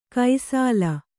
♪ kai sāla